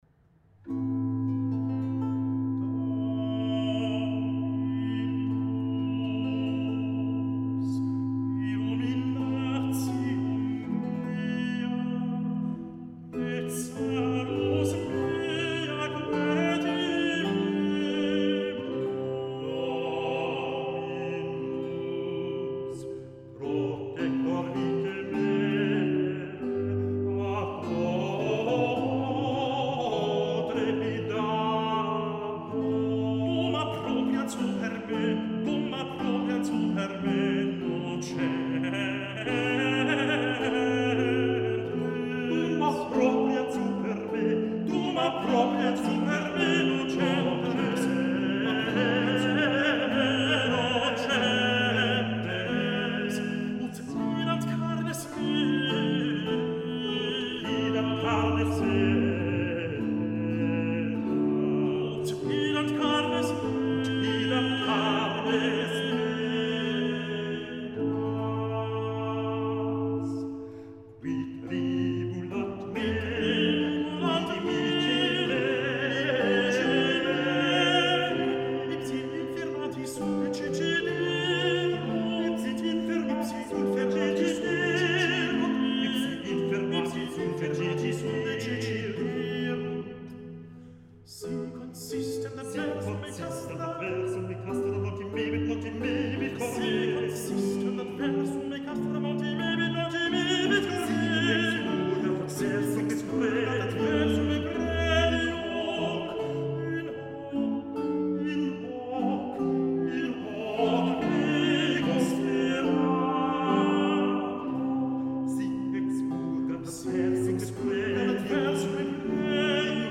Vêpres vénitiennes pour la naissance de Louis XIV
Cantus Cölnn                    (Harmonia Mundi)